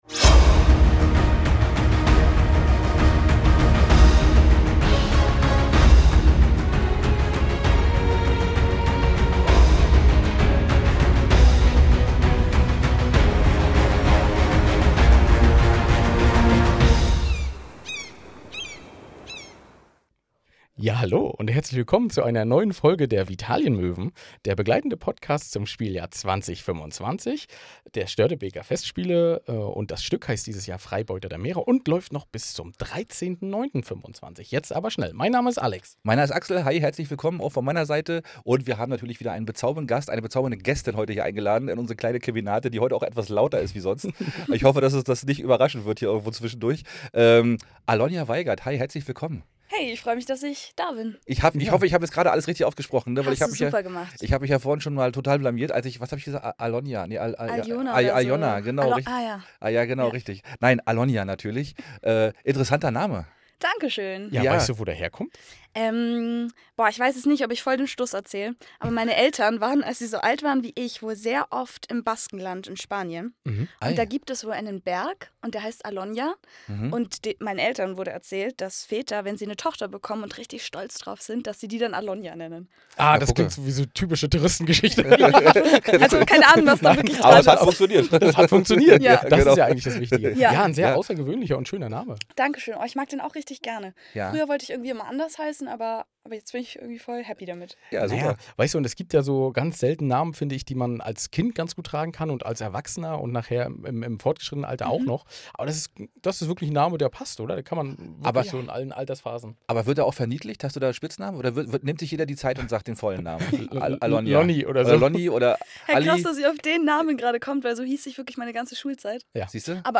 PS: Warum ziehen sich eigentlich alle Ladys beim Interview die Schuhe aus?